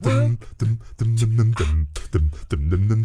ACCAPELLA10F.wav